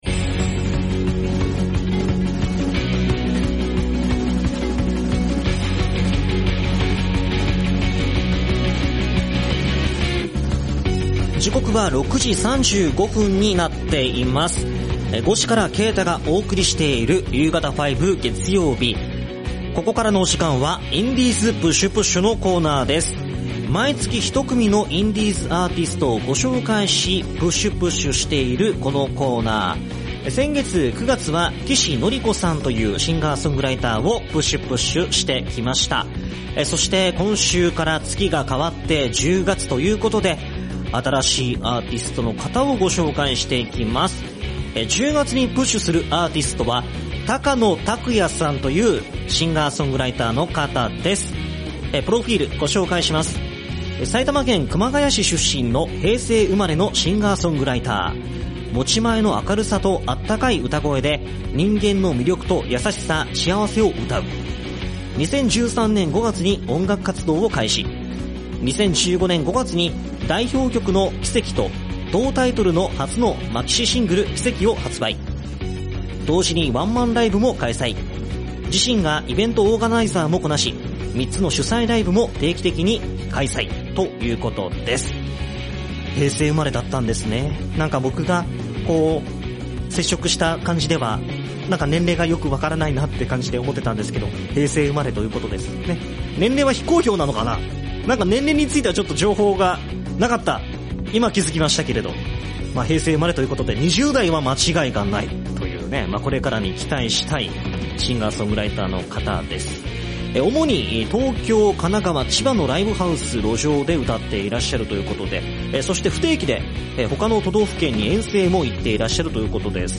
今週の放送同録音源はこちら↓
まあ曲は体育会系ではなくしっとり系なんですけどね！